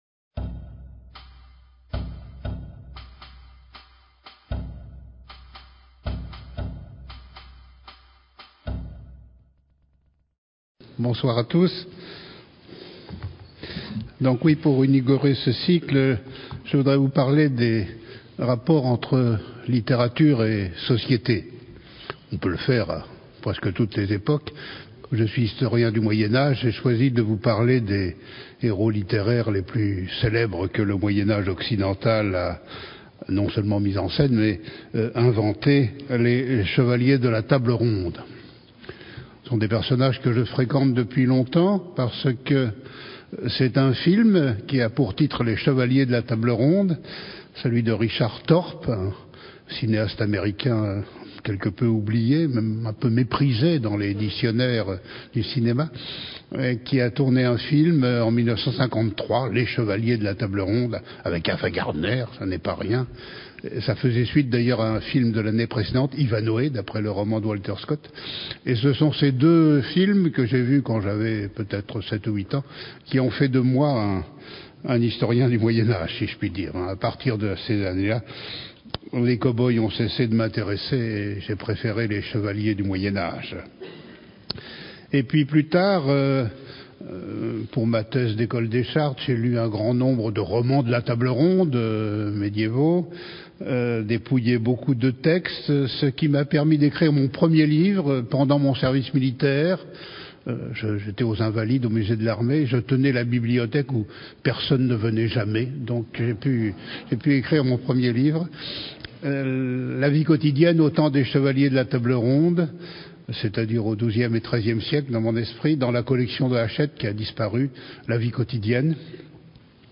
heros-arthurien-Michel-Pastoureau-Conference-compresse.mp3